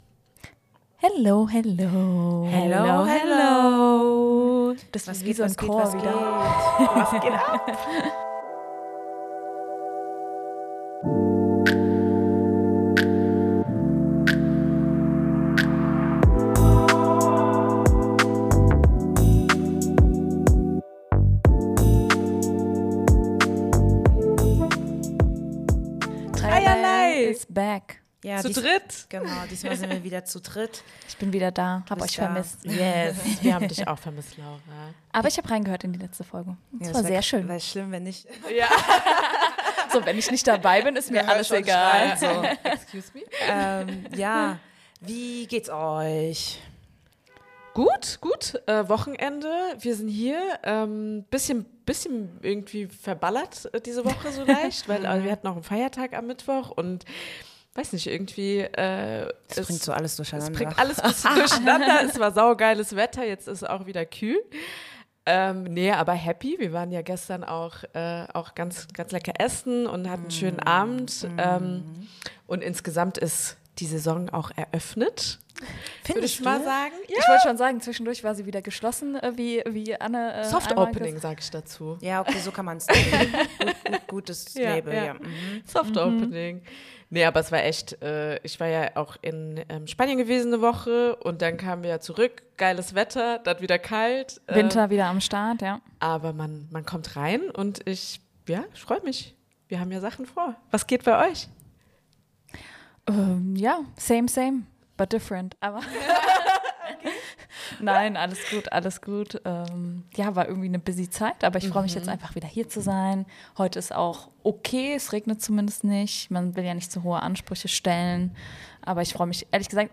In unserer neuen Folge sprechen wir zu dritt über den Trend „Solo-Dates“ - von persönlichen Erfahrungen damit über Schwierigkeiten beim Alleinsein bis zu Tipps zum Ausprobieren.
Dabei haben wir Support von Freund:innen in Form von Sprachnachrichten erhalten, die ihre Perspektiven auf das Thema teilen.